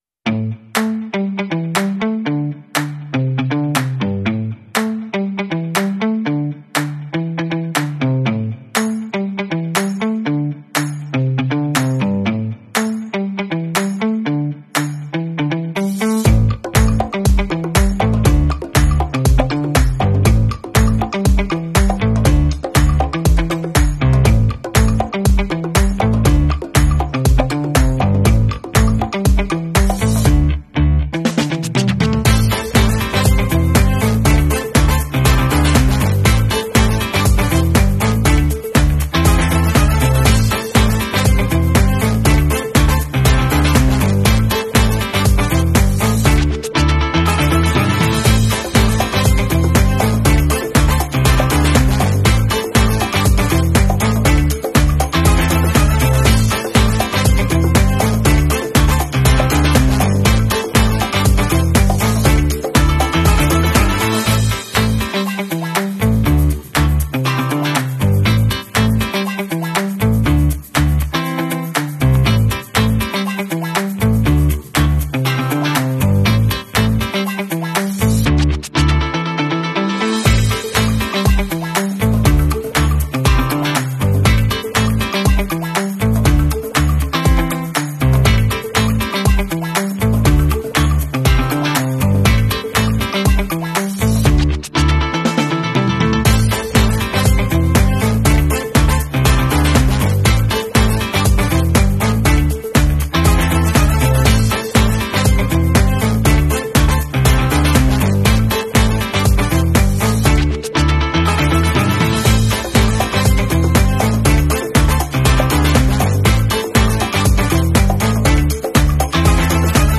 H C06 hanging co2 jet, easily sound effects free download